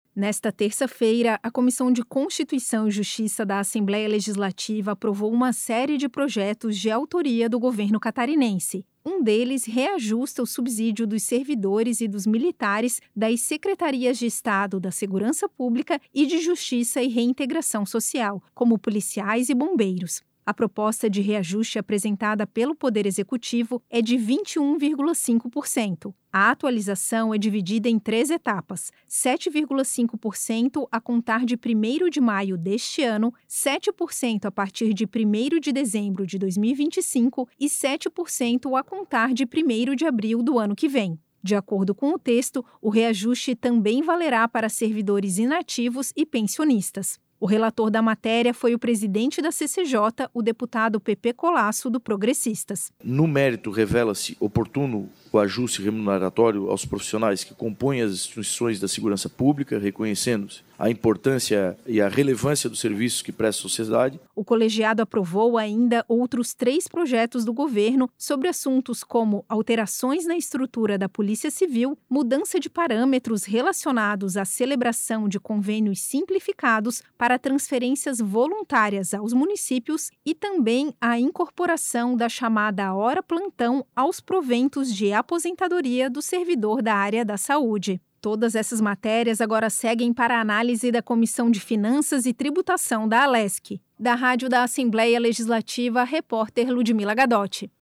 Entrevista com:
- deputado Pepê Collaço (PP), presidente do CCJ e relator do PLC 3/2025PL 20/2025, PL 49/2025 e PL 56/2025.